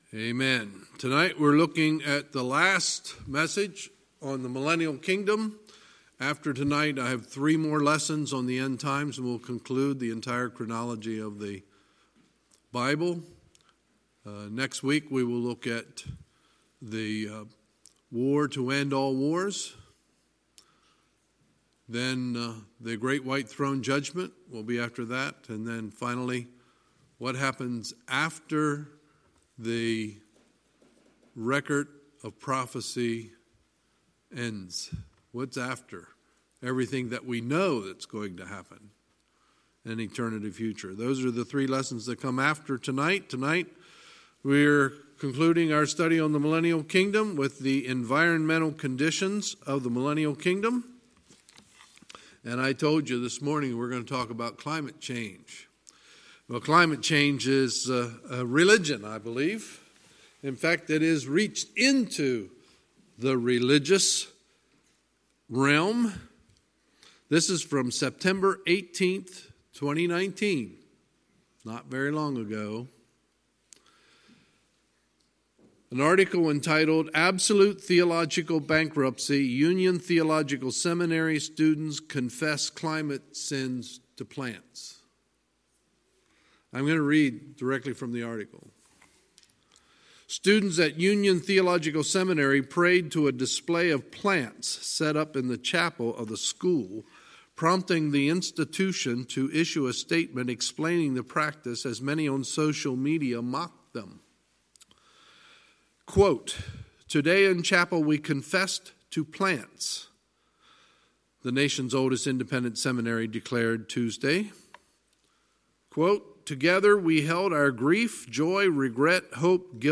Sunday, October 27, 2019 – Sunday Evening Service